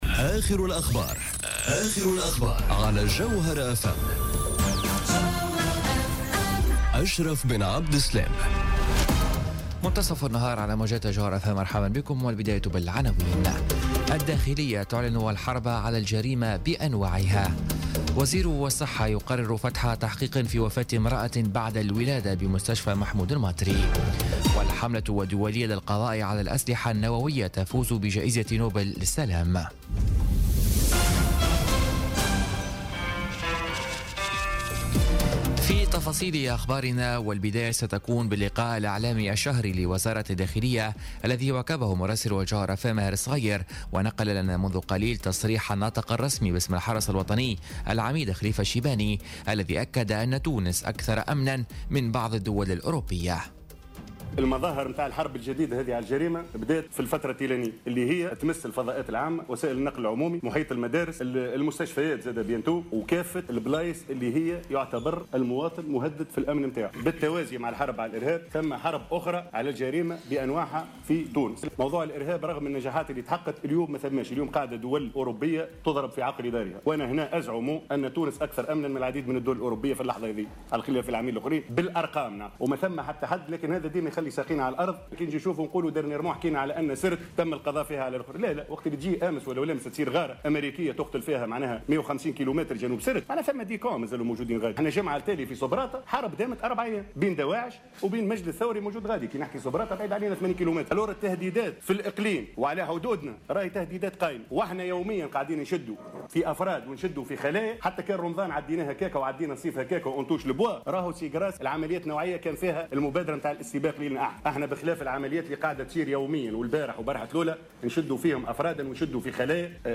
نشرة أخبار منتصف النهار ليوم الجمعة 6 أكتوبر 2017